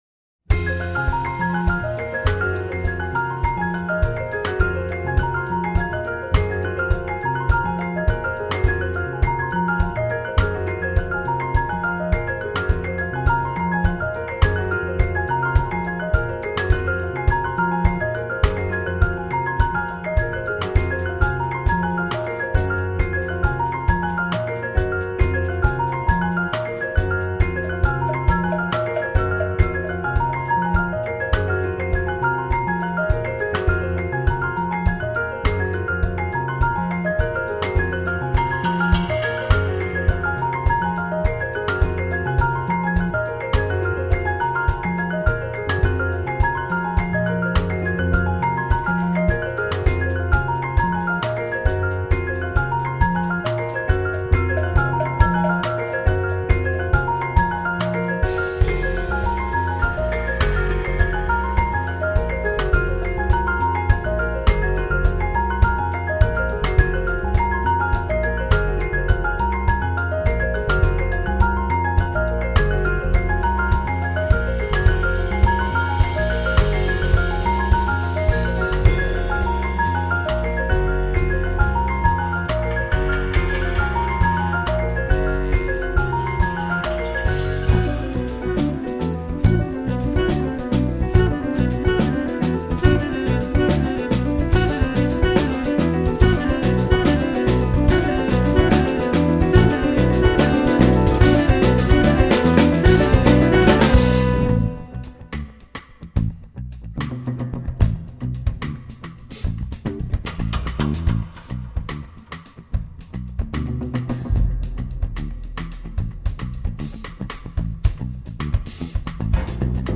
Minimal /Jazz / New Age.